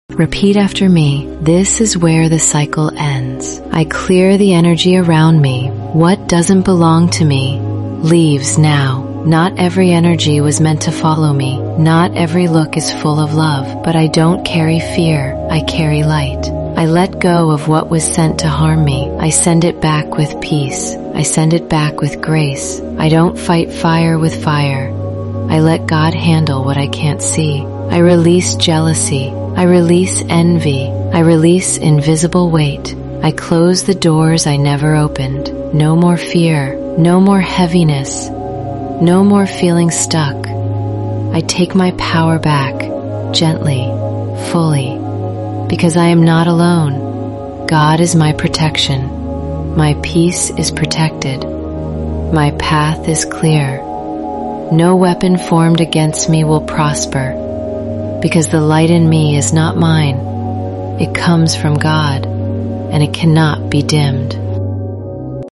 This is more than healing — it’s a return to peace. 417 Hz + 741 Hz for deep energetic cleansing.